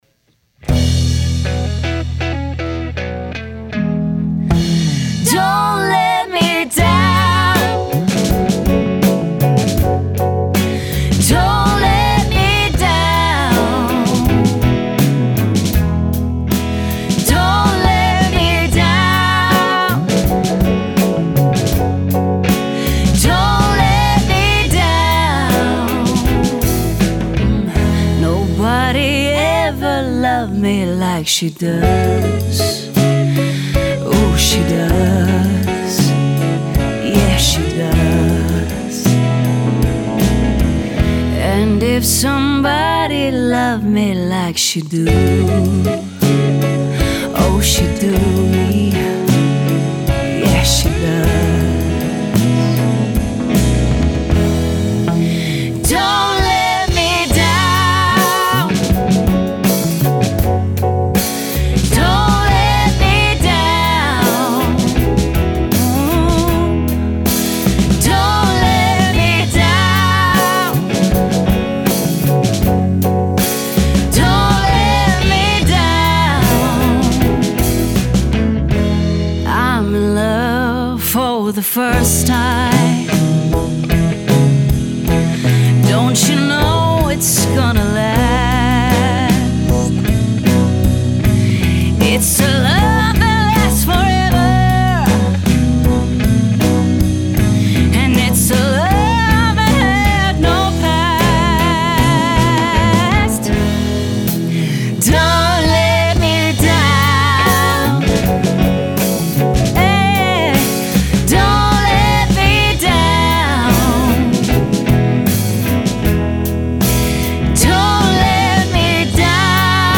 Klingt ganz gut!